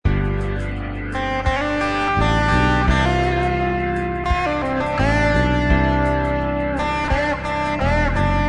Easy Blues Lick_30 – Guitar Alliance